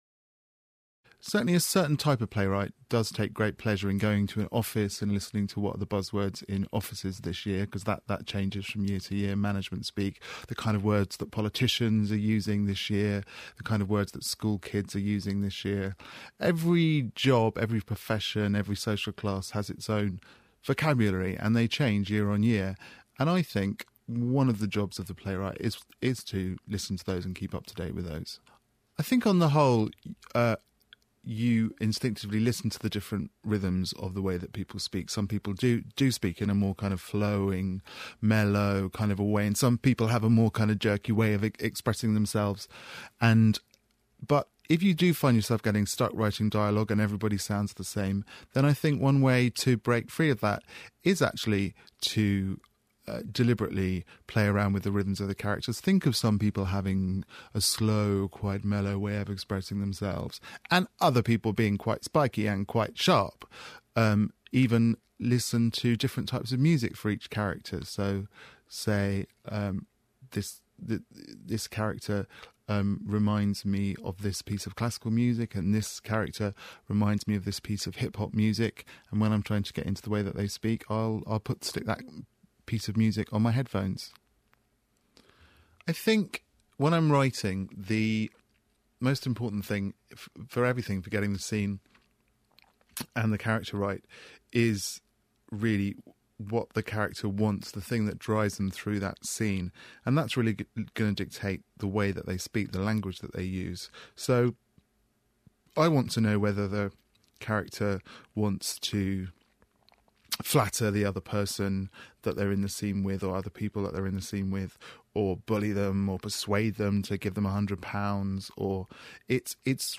So I was delighted when I stumbled across this recording on The Open University by playwright Mark Ravenhill, talking about his craft and his approach to writing: